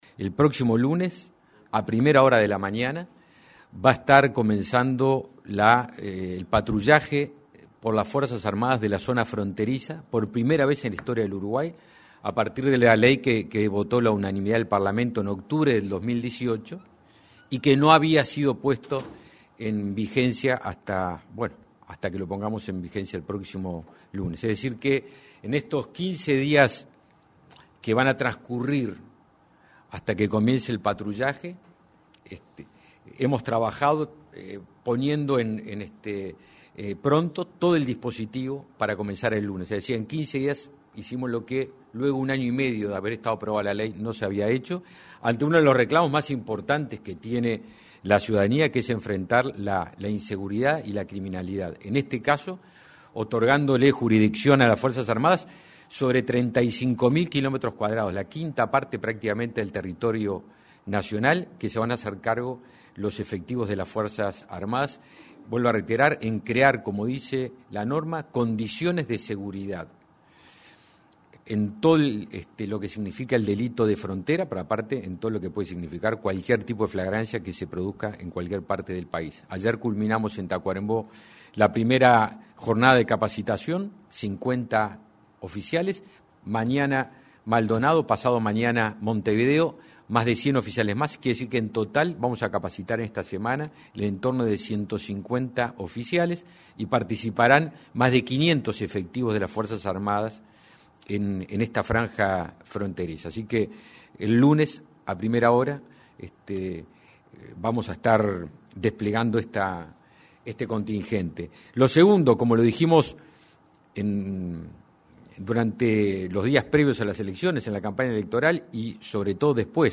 El avión presidencial se rematará, porque menos del 10 % de sus vuelos fueron de carácter sanitario y por sus altos costos de mantenimiento, confirmó el titular de Defensa Nacional, Javier García, tras el primer acuerdo ministerial con el presidente Luis Lacalle Pou. Además, el jerarca informó que el 16 de marzo comenzará el patrullaje de frontera y reafirmó que todas las acciones de la cartera serán informadas al Parlamento.